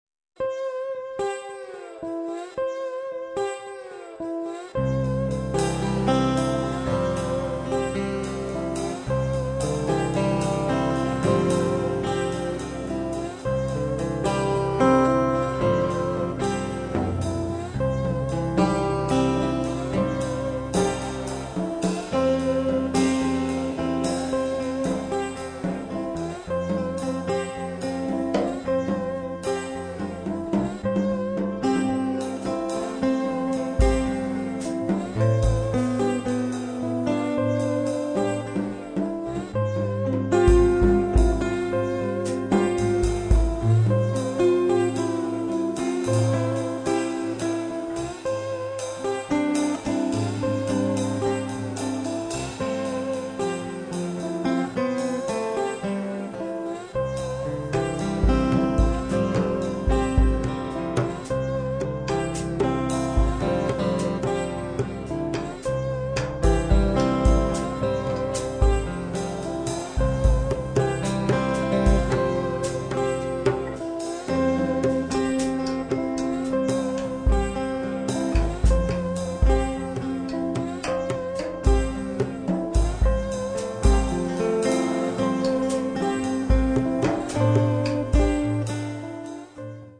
chitarra
batteria e percussioni